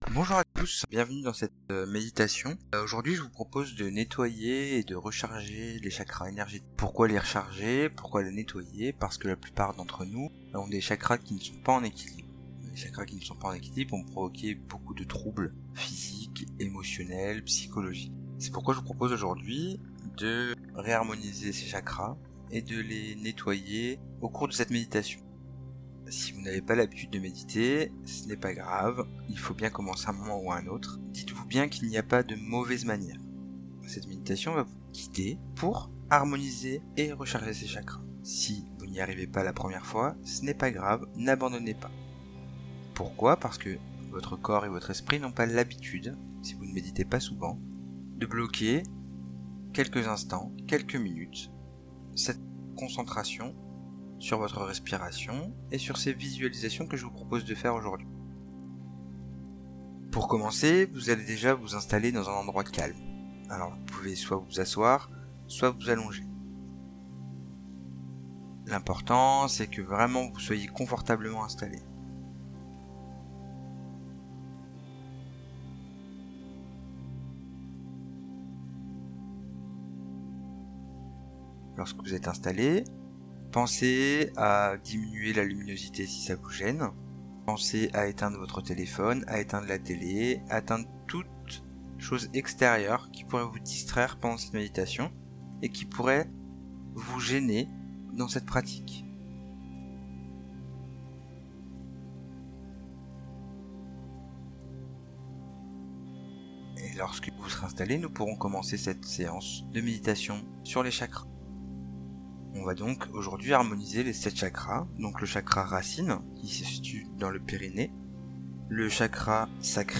Bonjour à tous,Vous trouverez dans ce post, un audio de méditation sur les chakras. Les chakras sont nos centres énergétiques et régulent l'énergie qui circulent en nous.